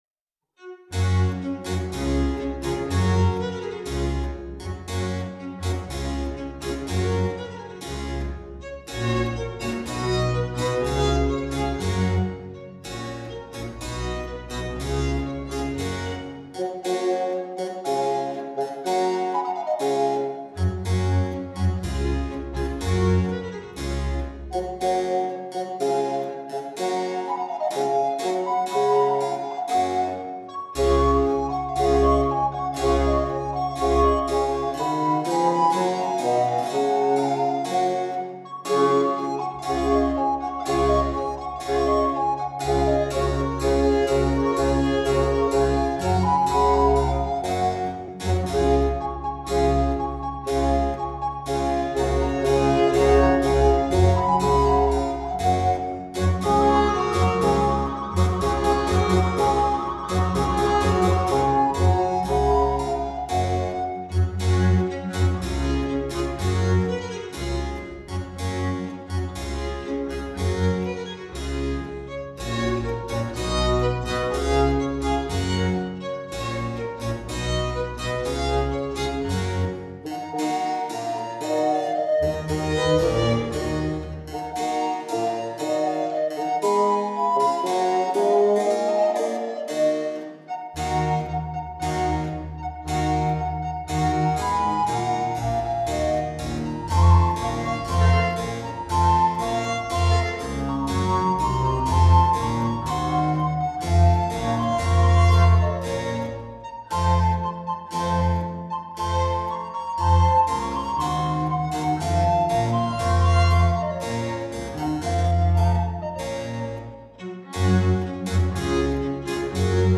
Música tradicional